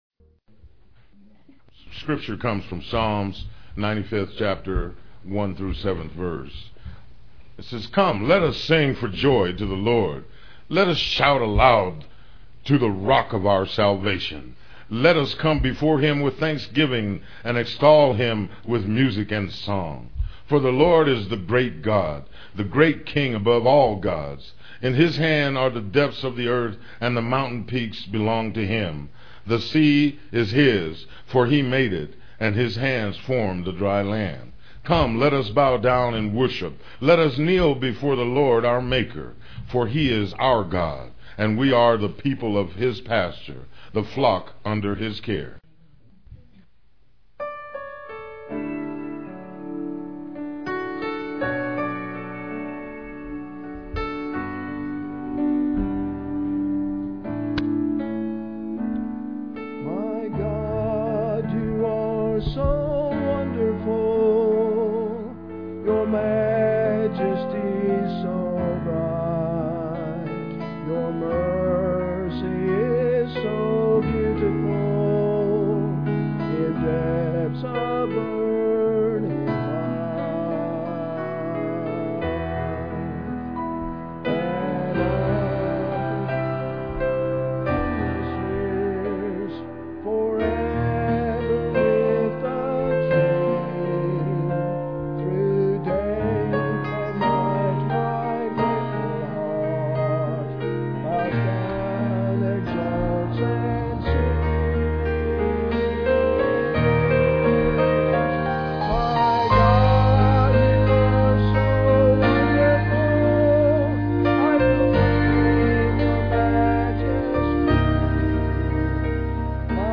PLAY Thanks Living, Part 1, Nov 12, 2006 Scripture: Psalm 95:1-7. Scripture Reading
Solo